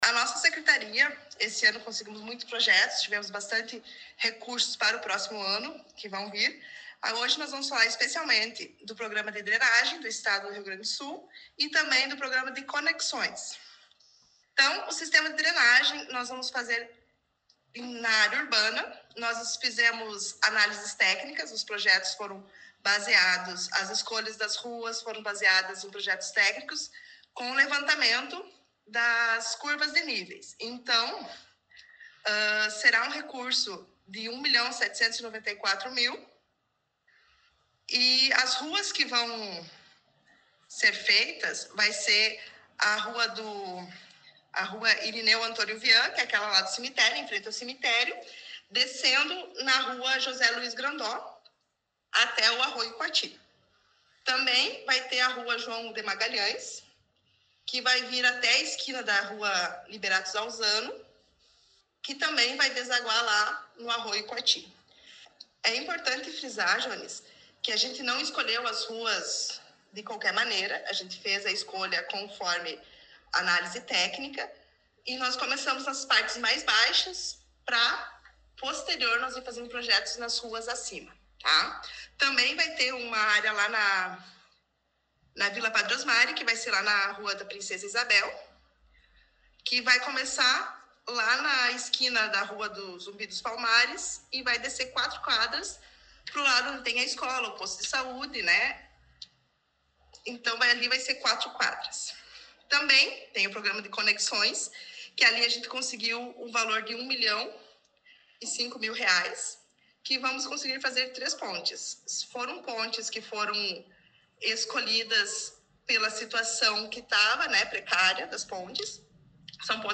Secretária Municipal do Meio Ambiente e Saneamento Básico concedeu entrevista